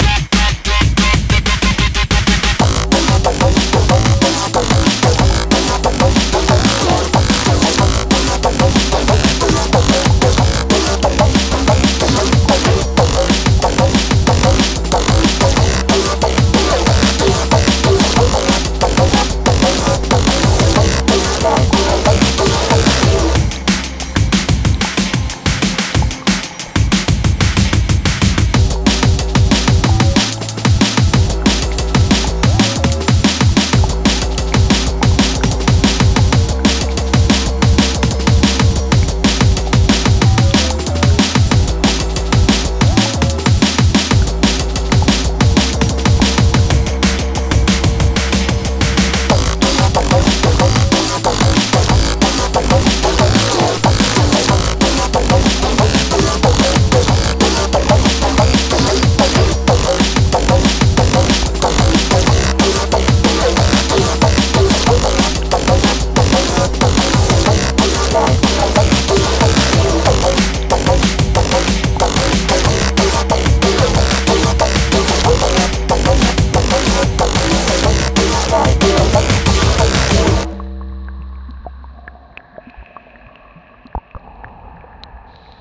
Firey DnB
electronic breakcore